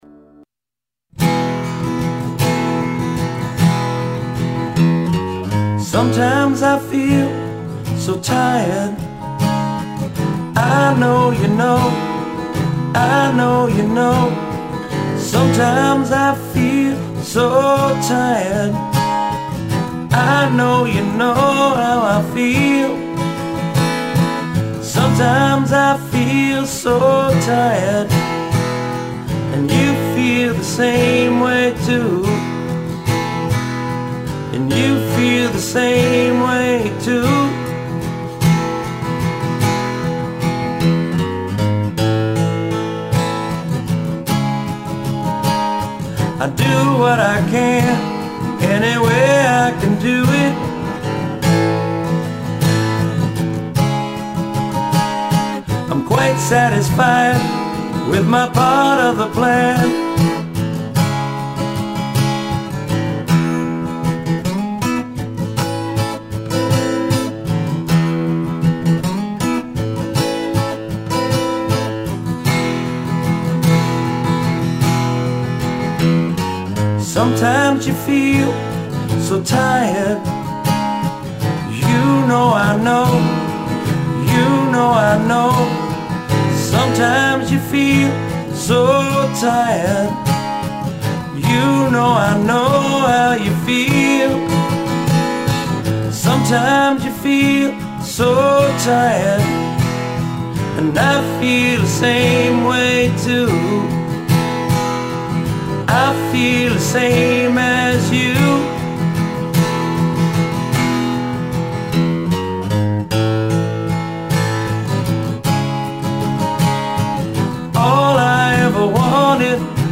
All solo performances